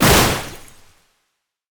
ZombieSkill_SFX
sfx_skill 05_2.wav